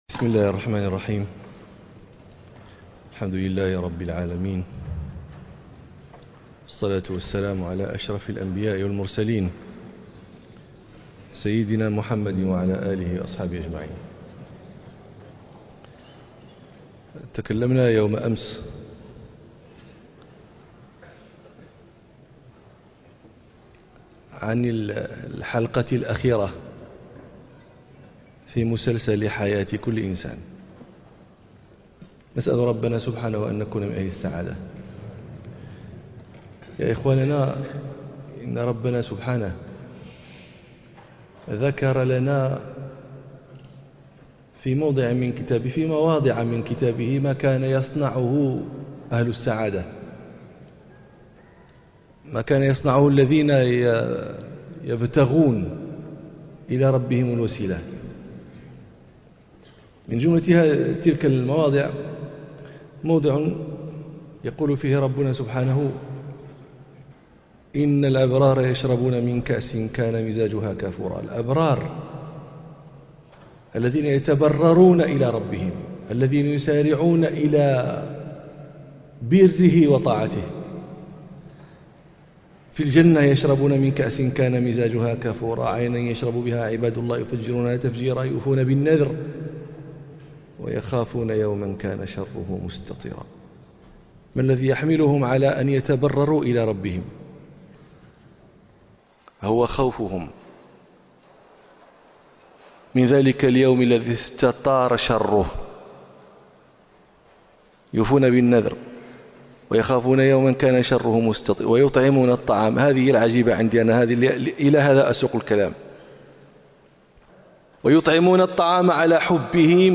موعظة عجيبة _ حَالُ الأَبْرَارِ فِي الدُّنْيَا وَ يَوْمَ الْحَشْرِ